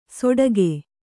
♪ soḍage